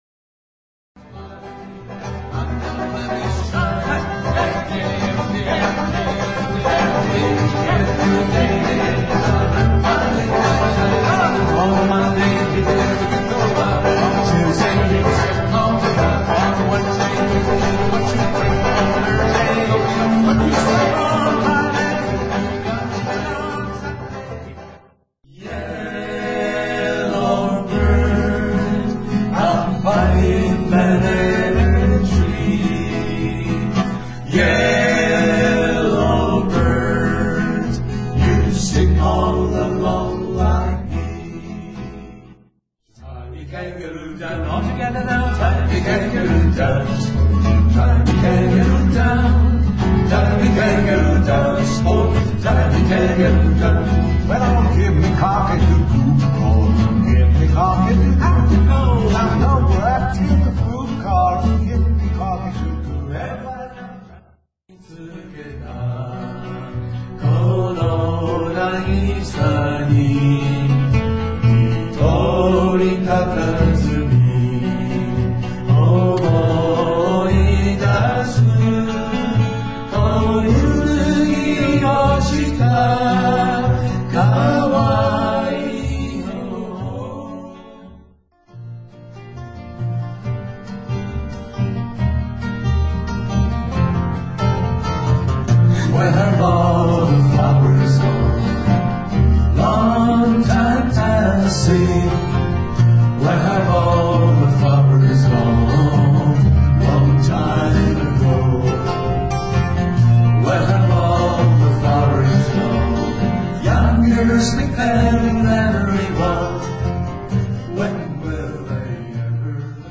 ひさびさの練習
8月のキングストン大会以来ひさびさの練習が10月１０日ありました。